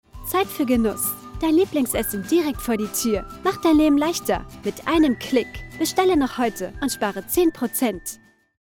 Best Female Voice Over Actors In December 2025
Teenager (13-17) | Yng Adult (18-29)